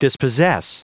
Prononciation du mot dispossess en anglais (fichier audio)
Prononciation du mot : dispossess
dispossess.wav